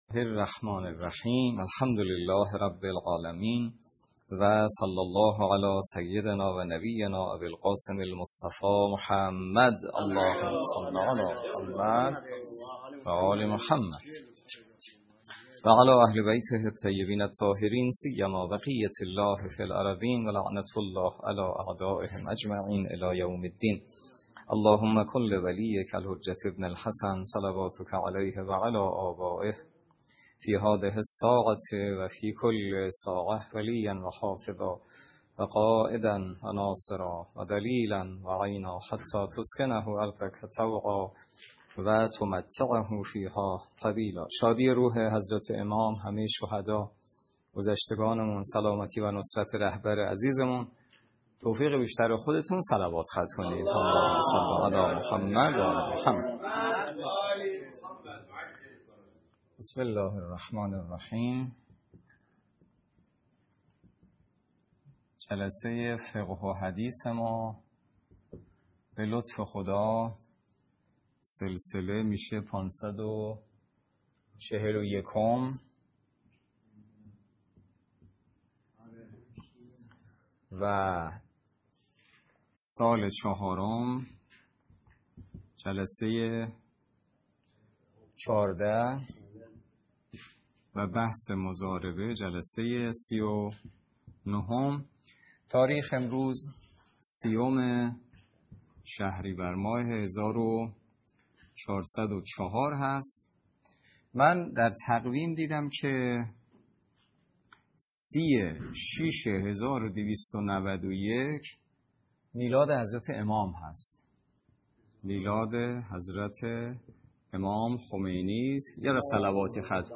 در محضر معصوم روایات ابتدای درس فقه